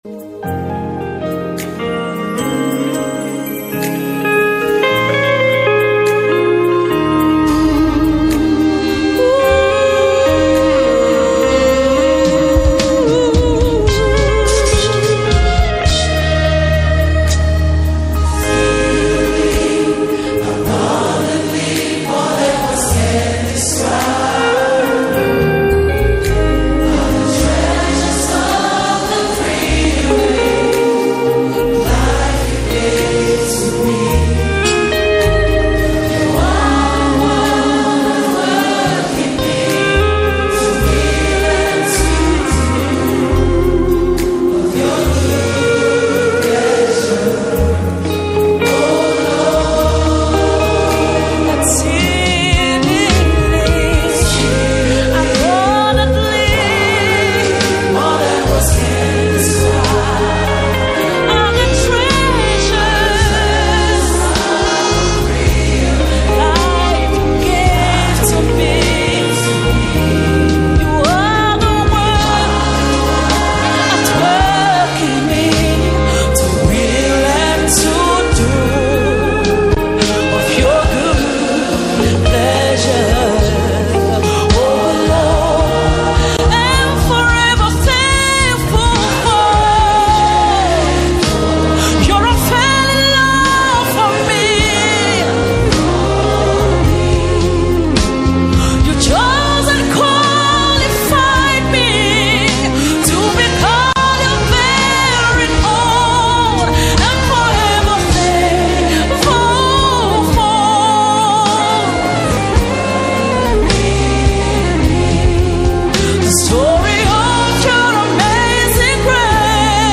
Key – A flat